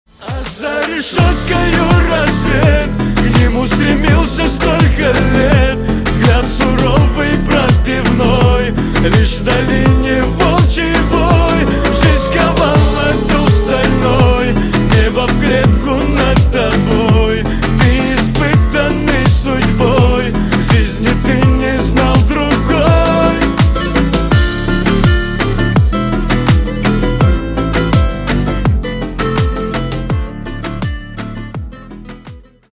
Кавказские Рингтоны
Шансон Рингтоны